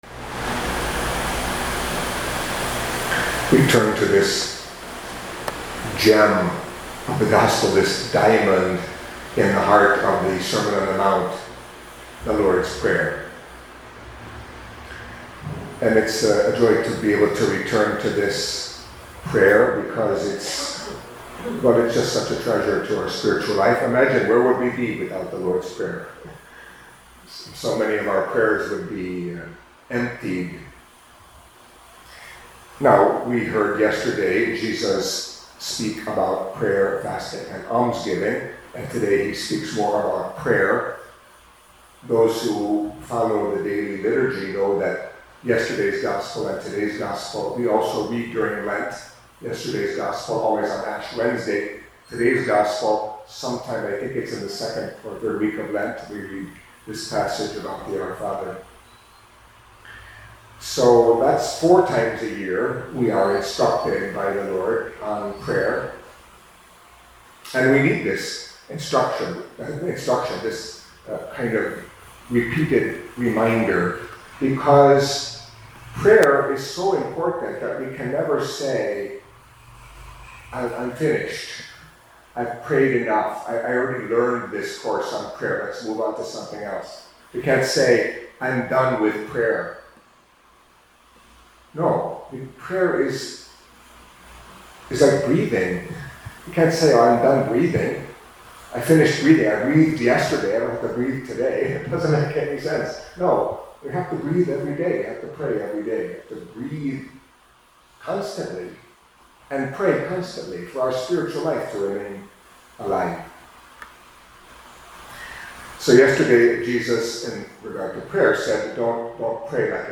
Catholic Mass homily for Thursday of the Eleventh Week in Ordinary Time